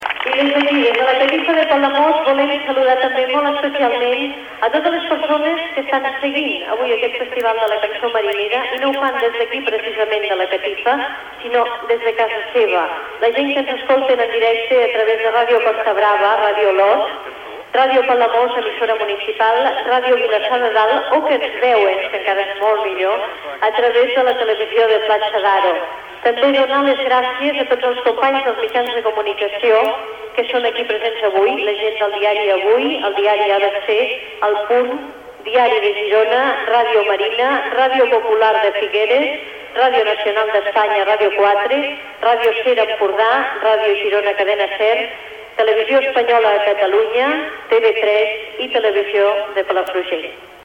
Connexió amb la transmissió del Festival de la Cançó Marinera de Palamós.
Salutació a totes les emissores que el transmeten per part de la presentadora del festival, la locutora Pepa Fernández